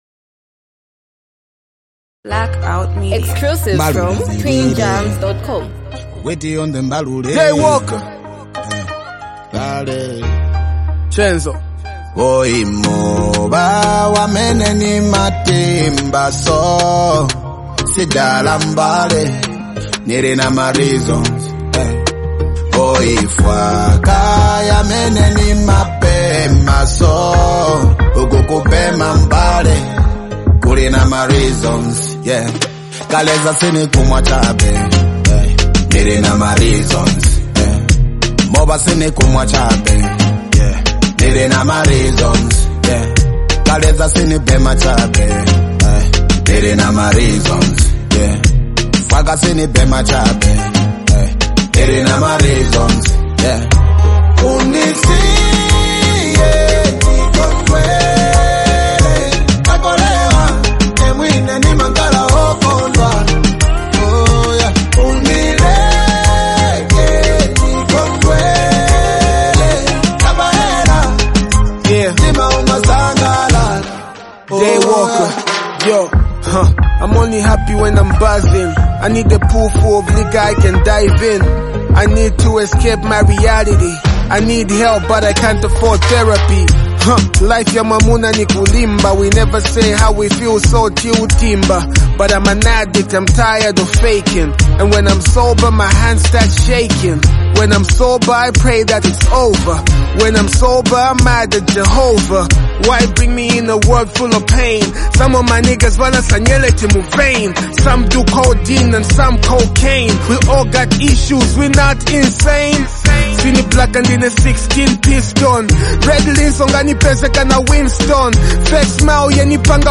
a smooth, emotionally expressive track